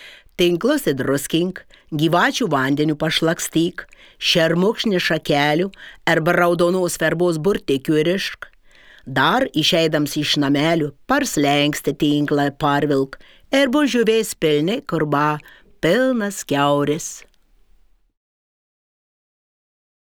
Pasiklausyk šišioniškai